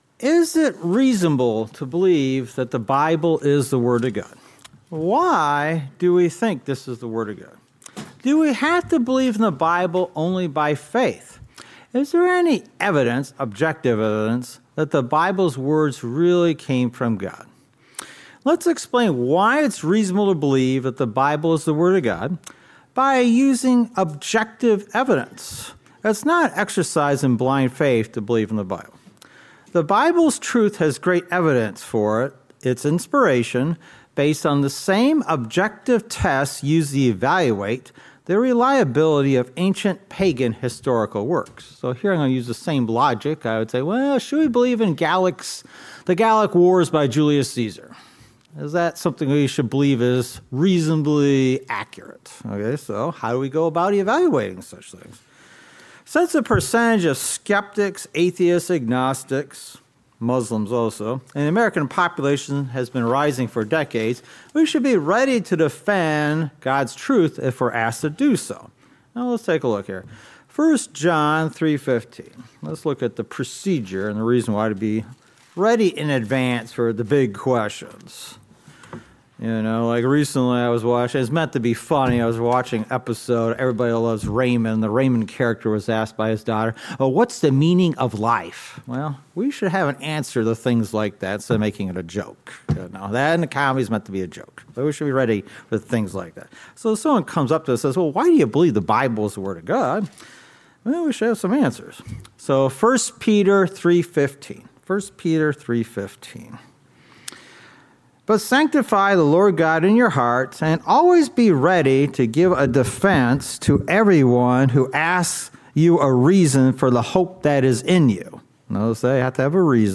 Sermons
Given in Ann Arbor, MI